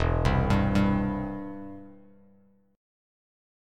Em#5 Chord